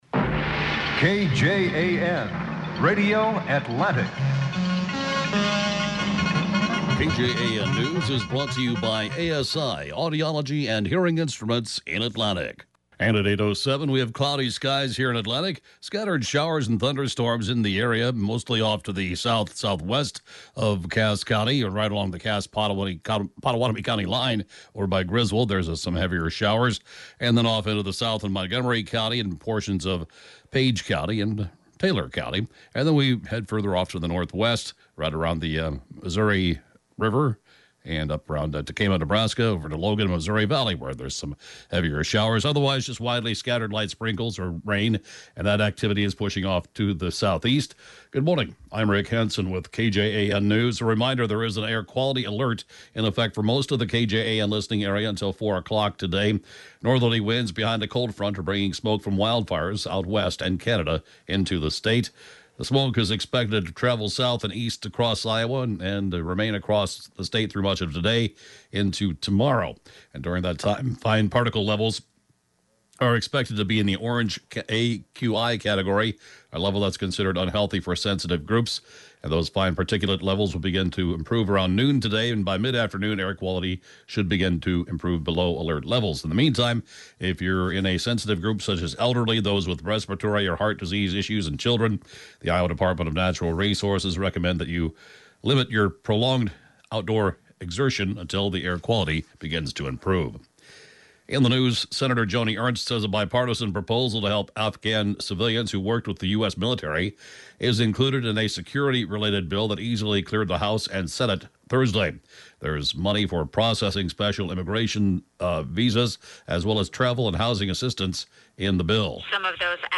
The broadcast News at 8:07-a.m.